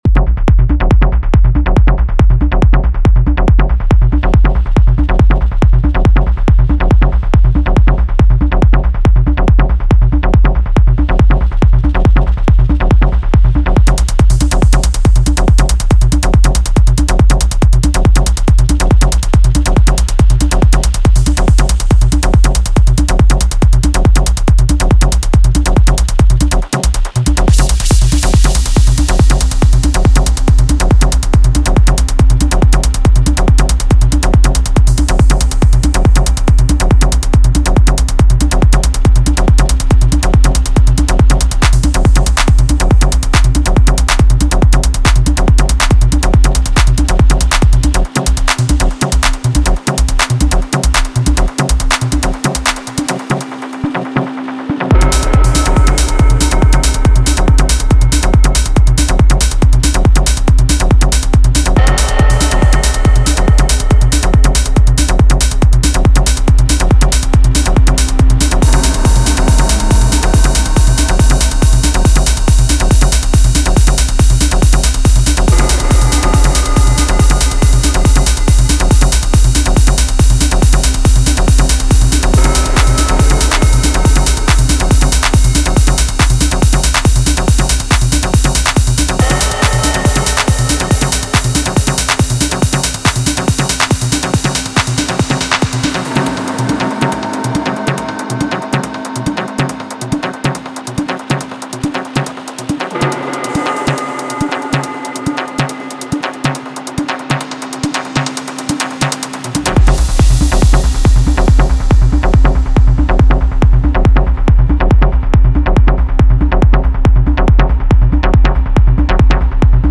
140 BPM